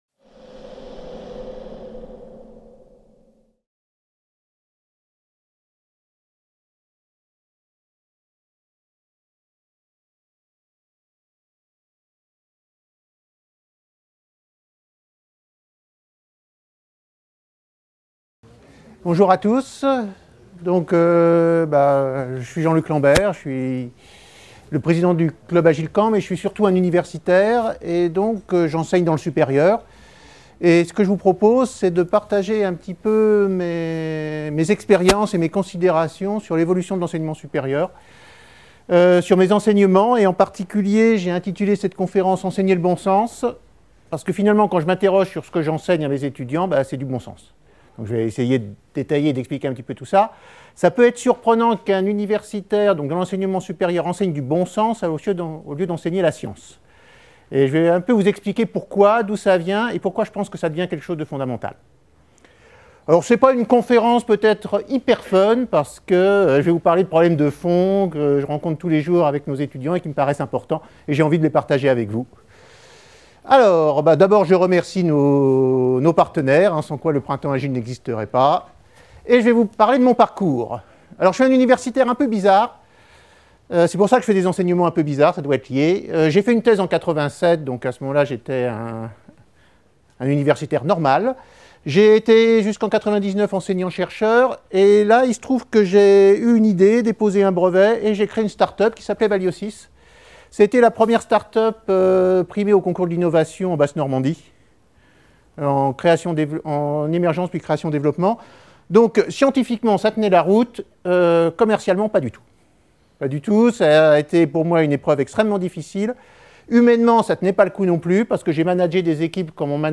La conférence : L’enseignement supérieur est surtout une transmission de connaissances de l’enseignant-chercheur vers les étudiants.